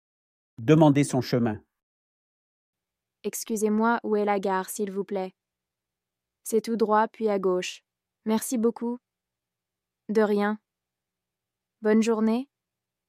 Dialogue FLE A1Dialogue FLE A1 - Demander son chemin | LivreFLE – Dialogues en français